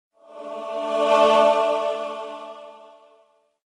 الأقسام : Message Tones
sweet